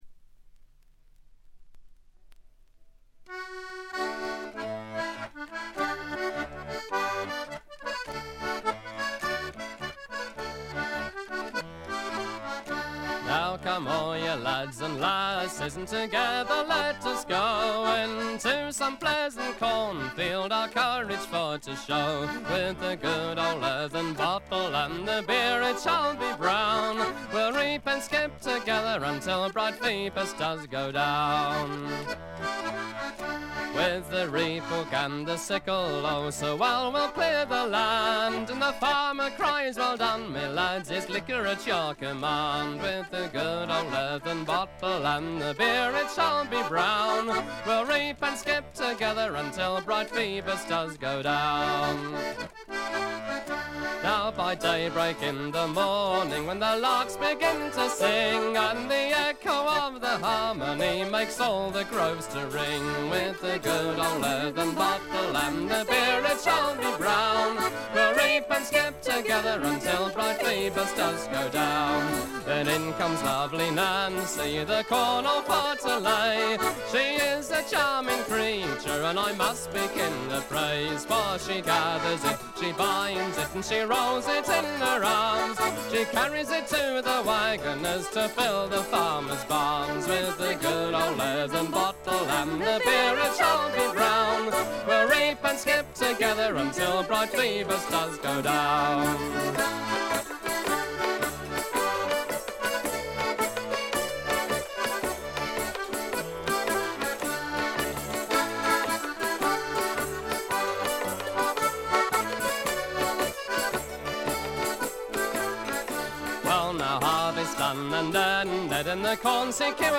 エレクトリック・トラッドの基本中の基本です。
試聴曲は現品からの取り込み音源です。